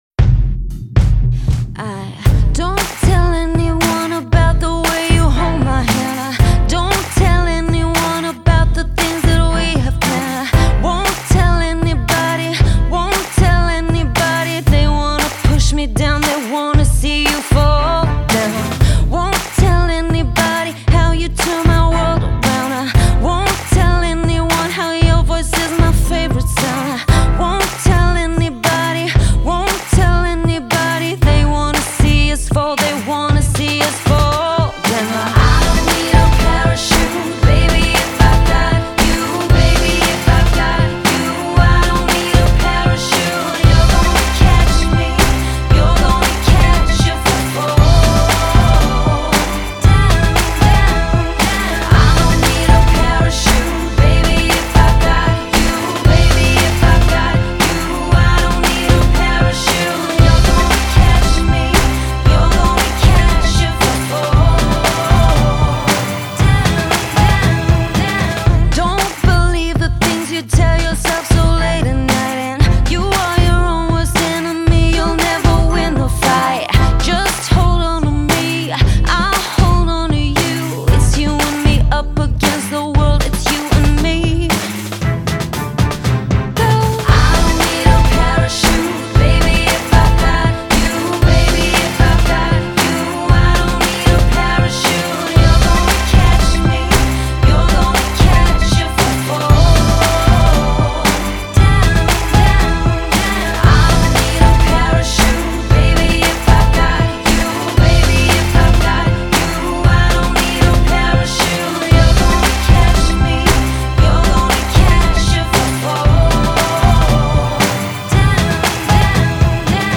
Жанр: R&B, club, HIP - HOP